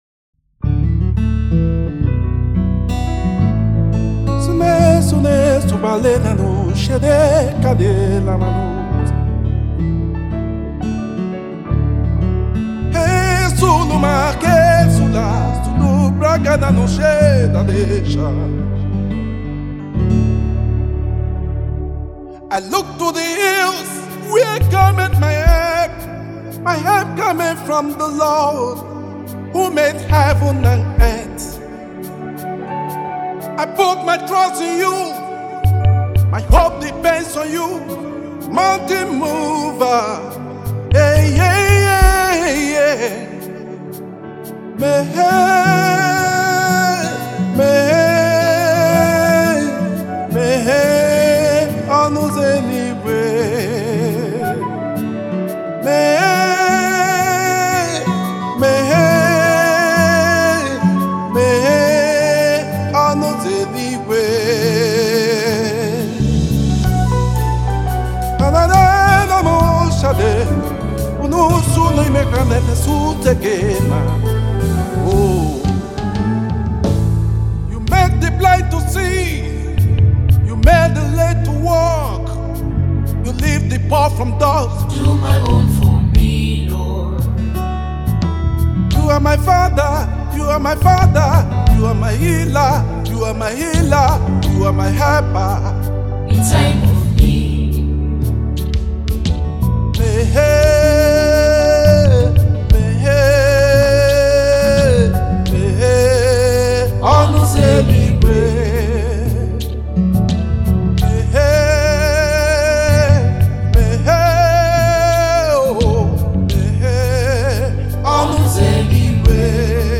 is a gospel music minister and inspirational singer